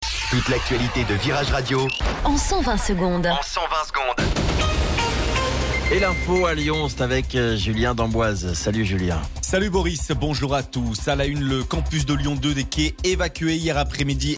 Flash Info Lyon